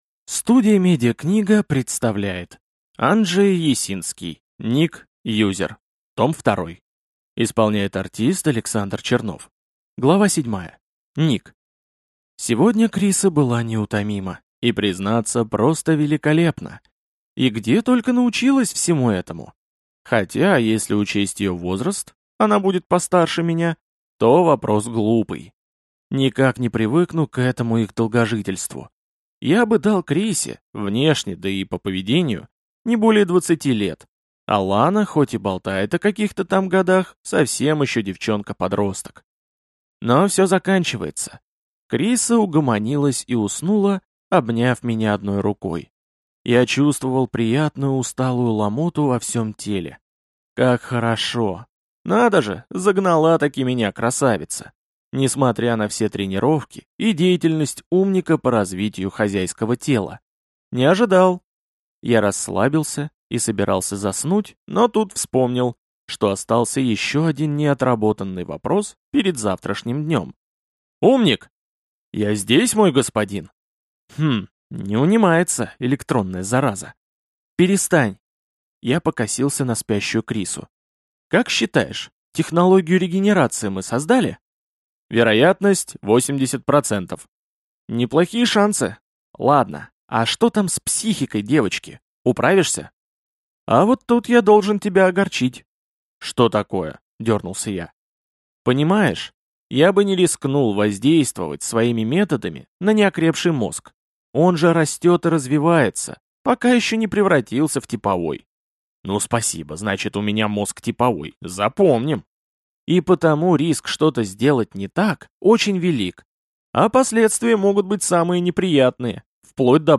Студия «МедиаКнига» представляет вторую аудиокнигу «Ник. Юзер. Том 2» популярной серии Анджея Ясинского – «Ник». Бывший ламер в инфомагии становится опытным пользователем.